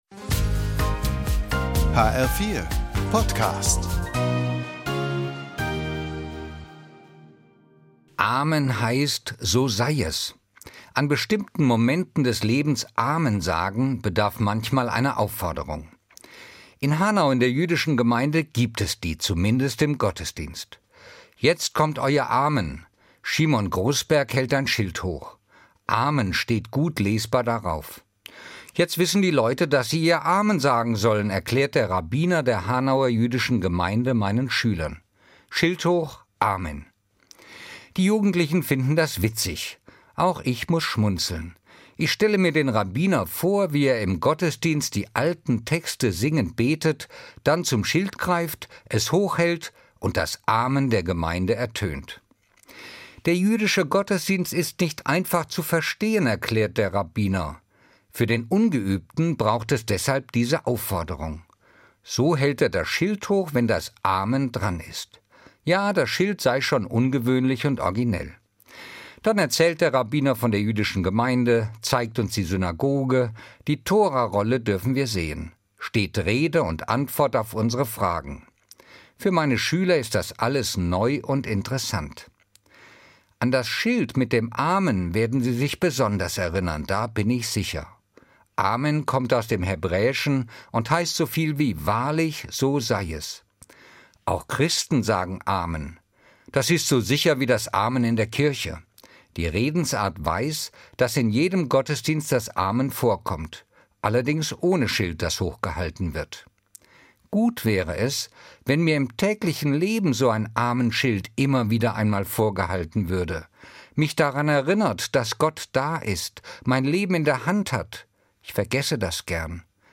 Evangelischer Pfarrer, Fulda